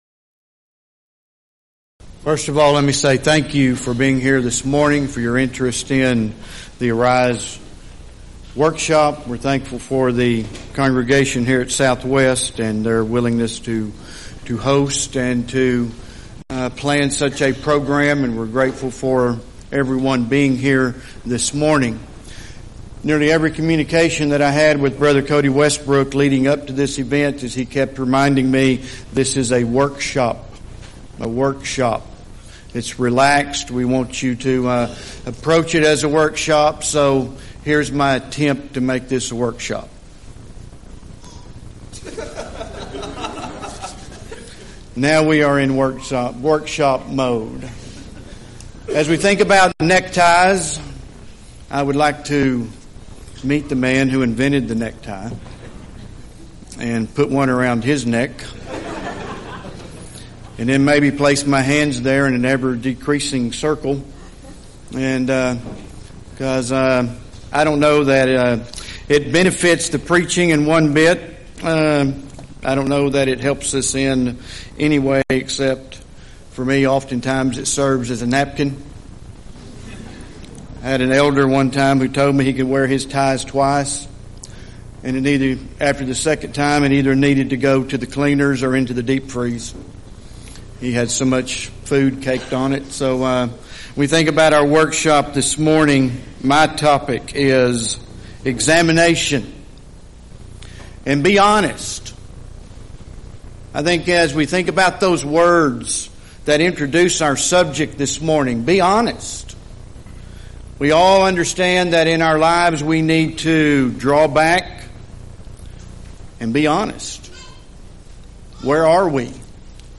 Event: 2nd Annual Arise Workshop
lecture